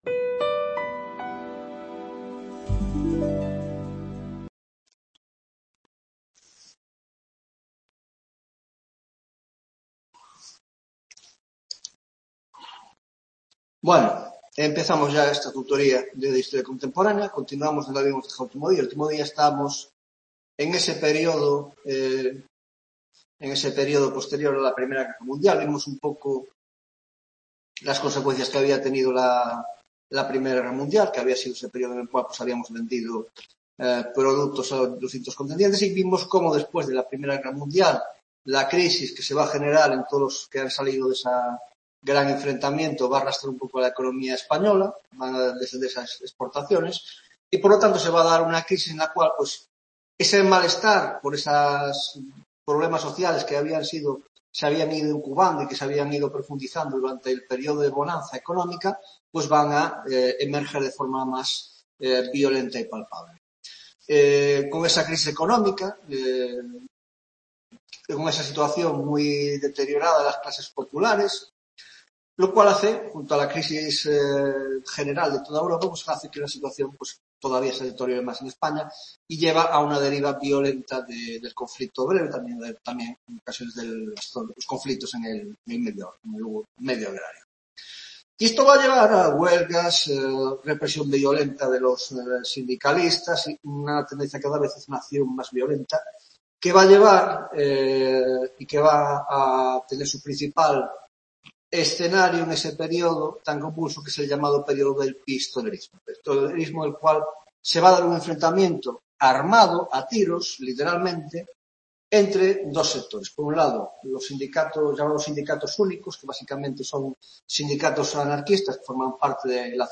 19ª tutoría de Historia Contemporánea